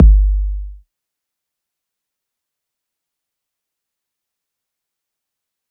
DB - Kick.wav